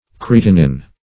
kreatinin - definition of kreatinin - synonyms, pronunciation, spelling from Free Dictionary Search Result for " kreatinin" : The Collaborative International Dictionary of English v.0.48: Kreatinin \Kre*at"i*nin\, n. (Chem.)